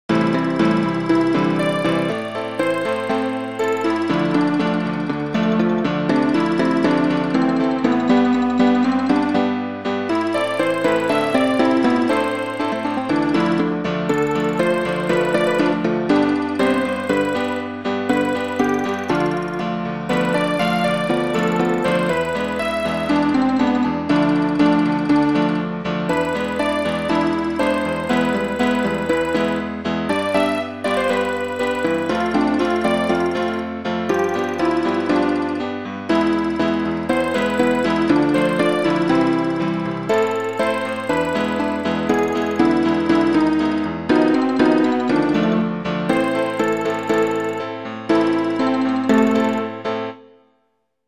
【拍子】4/4 【種類】ピアノ